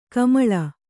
♪ kamaḷa